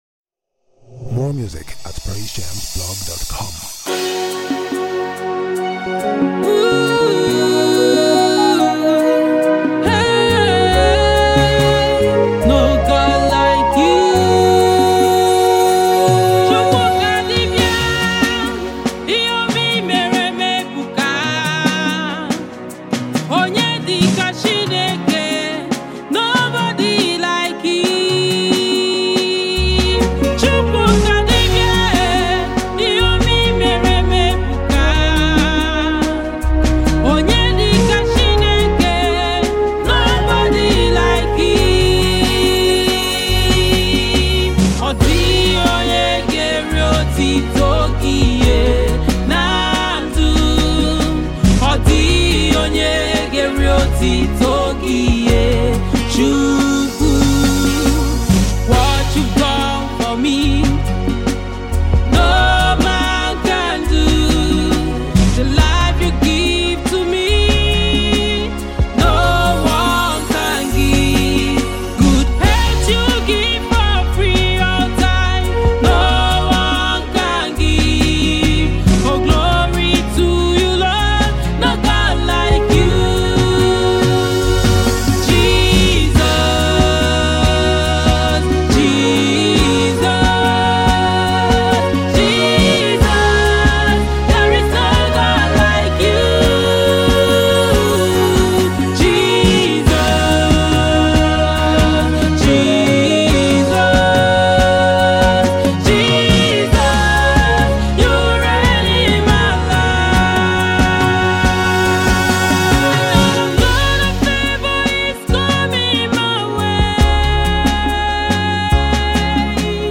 Nigerian gospel music minister
soul-lifting song
soul-lifting and powerful worship piece